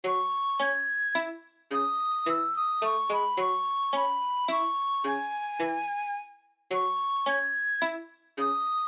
本当は森の神秘感を出したいけど、この作品にはハープが合わないなぁと思ってベンベンしました。